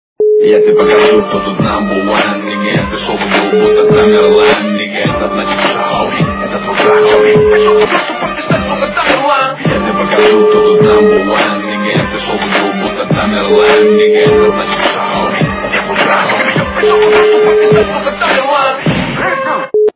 російська естрада
При заказе вы получаете реалтон без искажений.